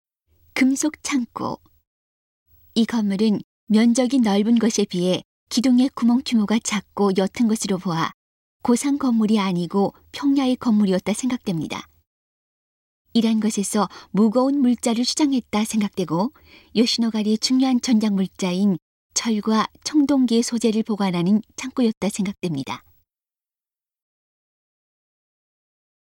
이러한 점으로 보아 무거운 물자를 저장했다 생각되고, 요시노가리의 중요한 전력물자인 철과 청동기의 소재를 보관하는 창고였을 거라 생각됩니다. 음성 가이드 이전 페이지 다음 페이지 휴대전화 가이드 처음으로 (C)YOSHINOGARI HISTORICAL PARK